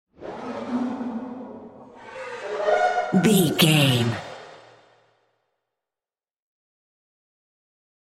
Groaning Devil Stinger.
In-crescendo
Aeolian/Minor
ominous
dark
haunting
eerie
strings
synth
pads